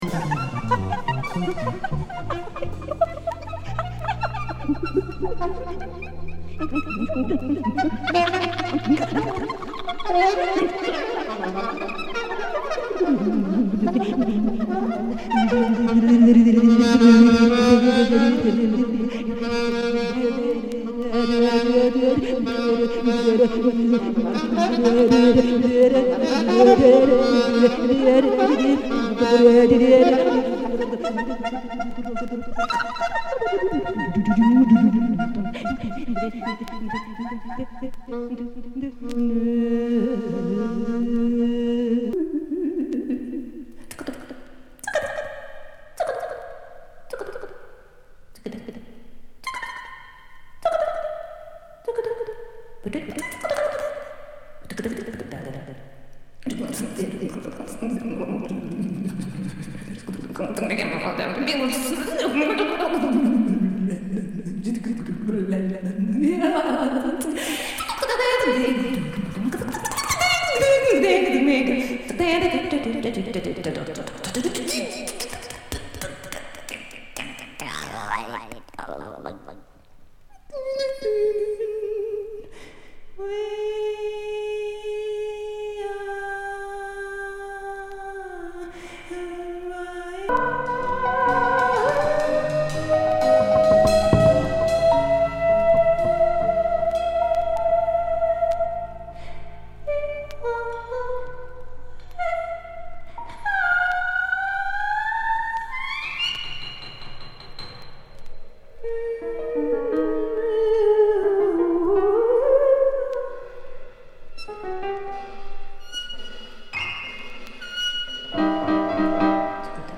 飛び交う極北イタコティック・ボーカル！